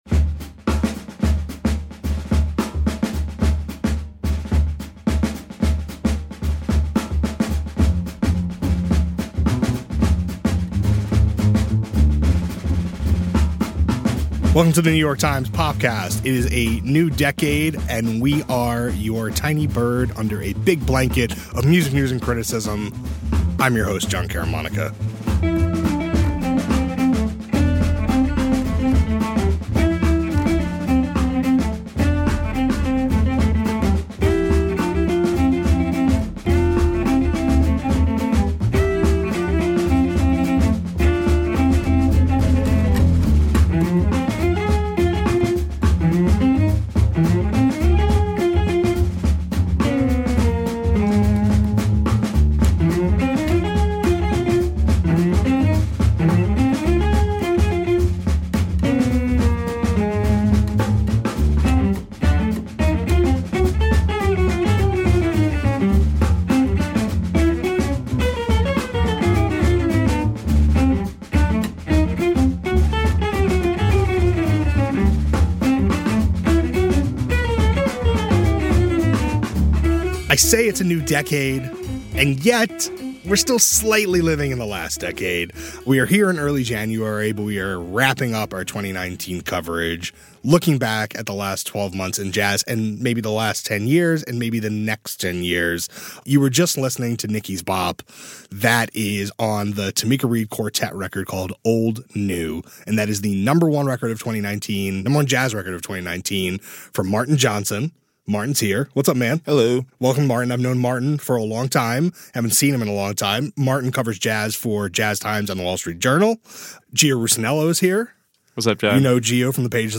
A conversation about the best jazz albums of the year, and what they tell us about the genre’s future.